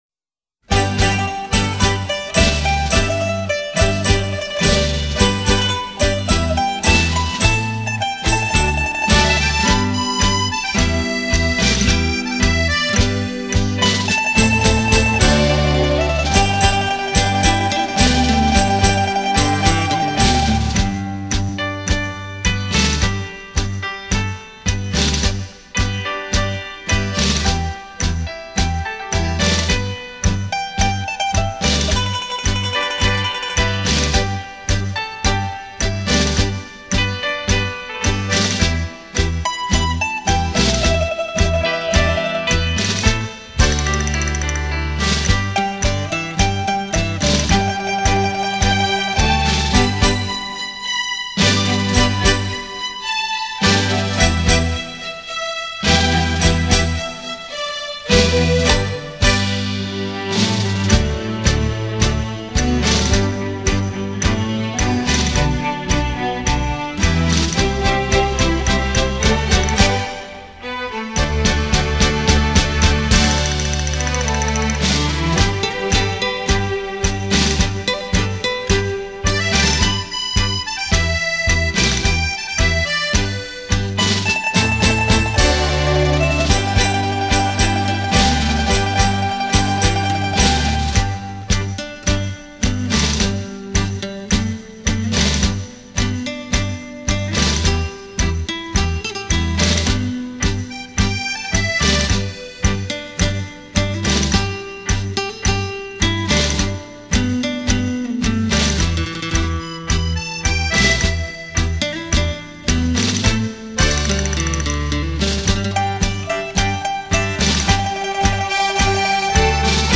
探戈至尊，曲曲动听。
优美的探戈旋律让人“醉”入其中．．． 　.